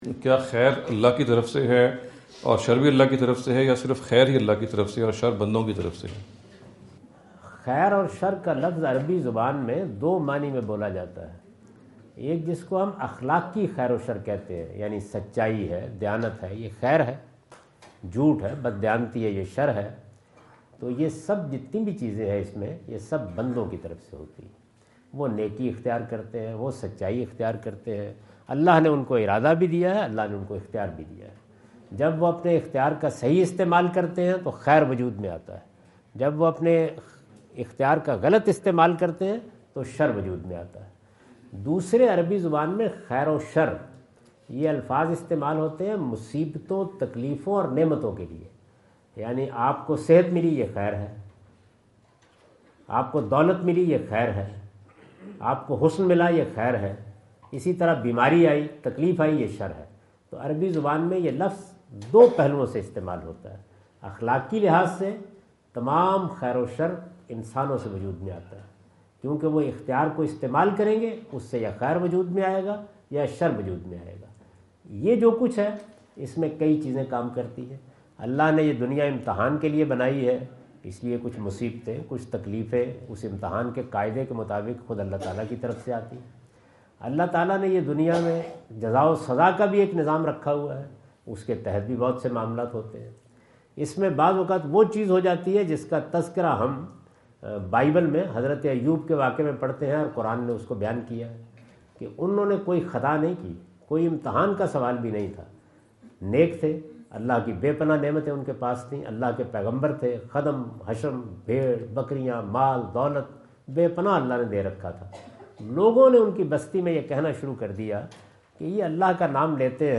Javed Ahmad Ghamidi answer the question about "Is Good (Khayr) and Evil (Sharr) From God?" asked at Corona (Los Angeles) on October 22,2017.